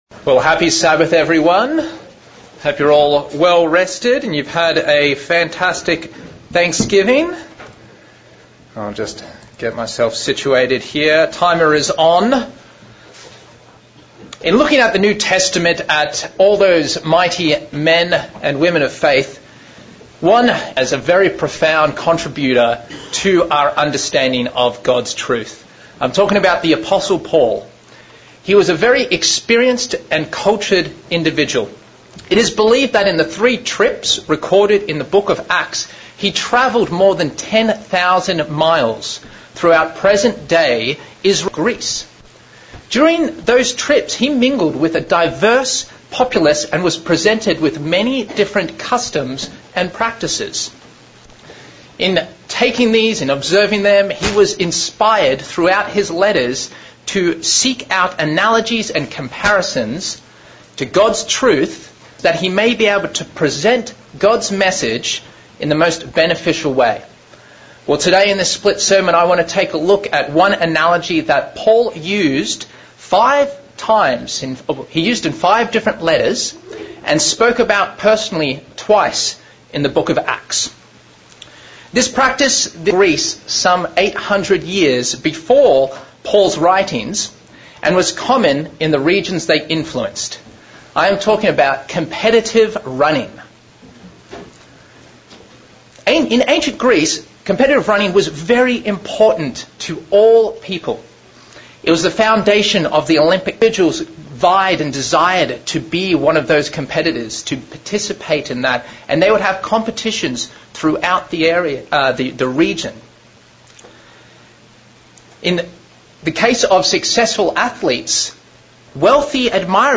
Split sermon looking at the topic of competitive running and comparing it to the scriptures Paul used exhorting us to run to win the prize